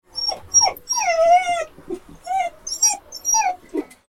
Dog-whimpering-sound-effect.mp3